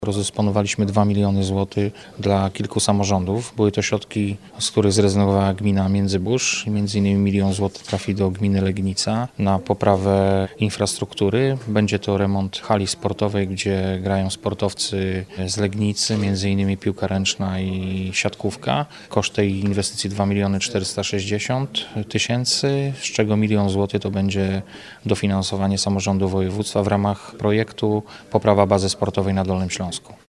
Legnica otrzymała dofinansowanie w wysokości miliona złotych, reszta środków będzie pochodziła z budżetu gminy, mówi wicemarszałek Wojciech Bochnak.